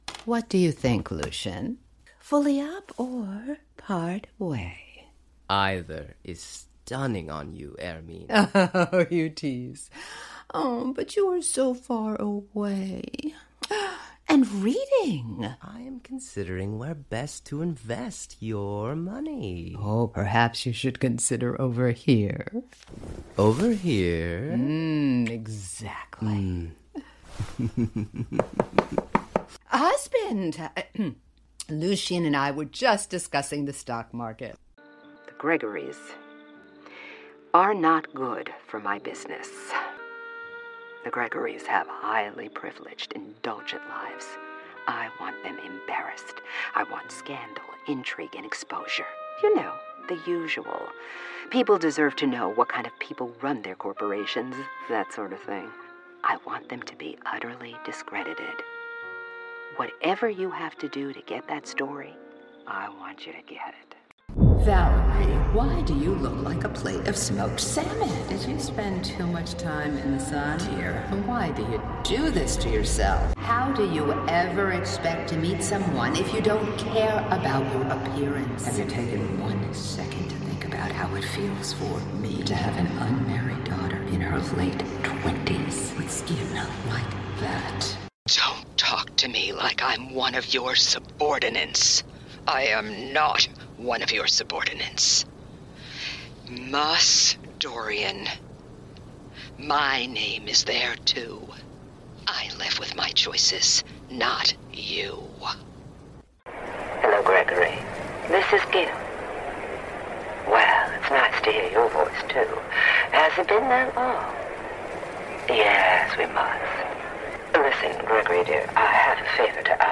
Professional Female Voice Actor, Voiceovers